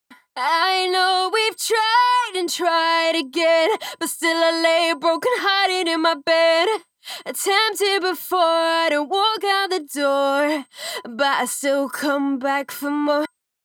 サウンドデモ
ボーカル（SA-3適用後）
SA-3_FemaleVocal_Engaged.wav